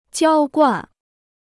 娇惯 (jiāo guàn): to pamper; to coddle.